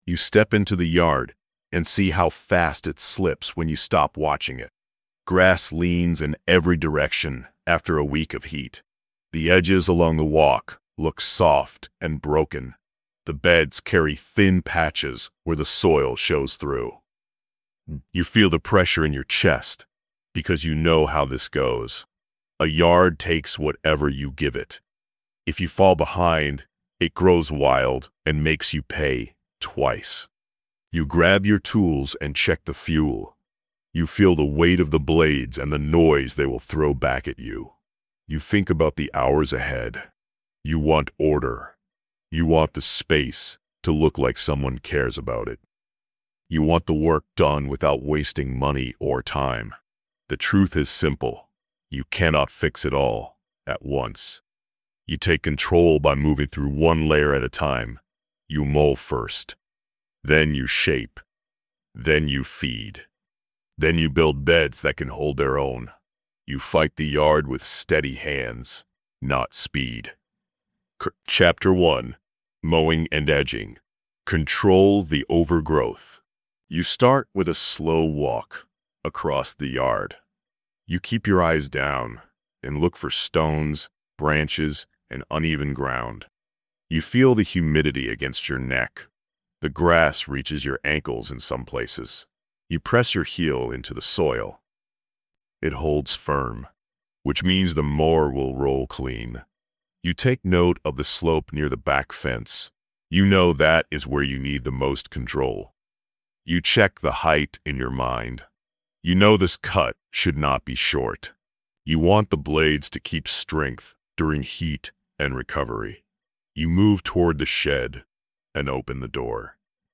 You learn how to shape the space without wasting money, how to read soil and conditions, and how to keep the yard alive through heat and stress. The tone stays gritty and grounded, built for people who want results and are willing to earn them.